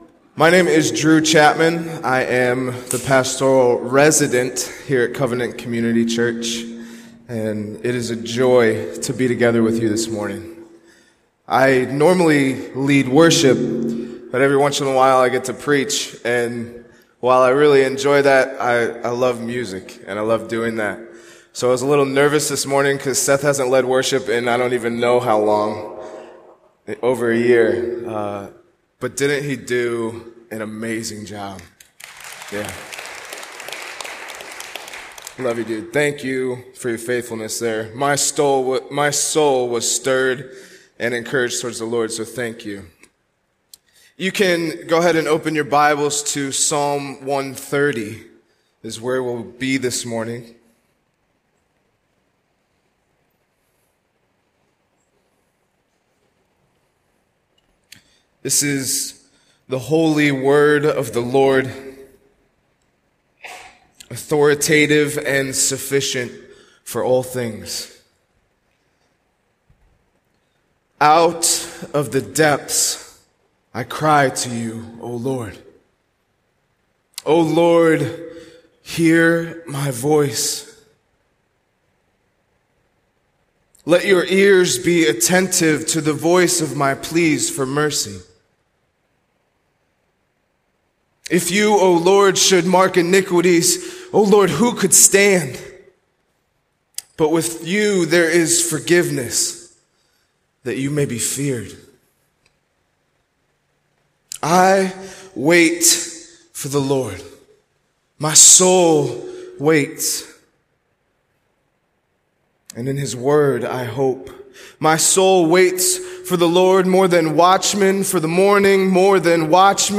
Sermon
A sermon from the series "Stand Alone Sermons."